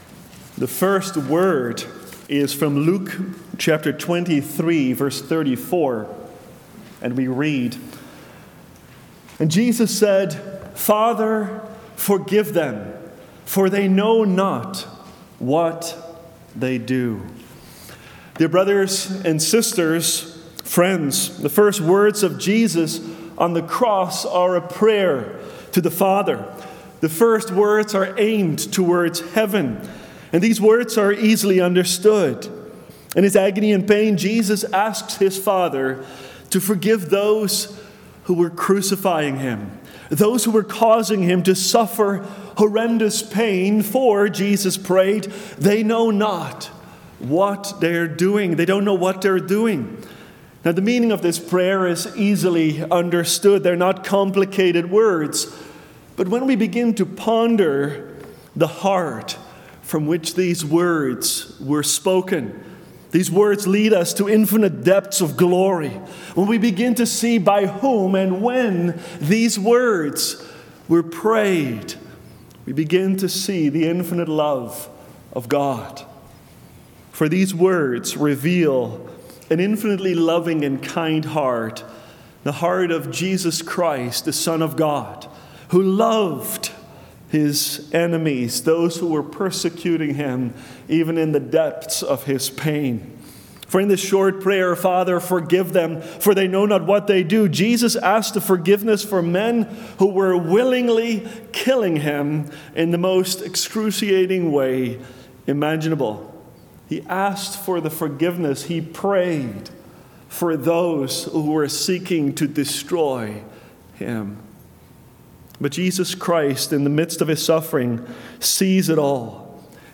Community Good Friday 2025 1st Word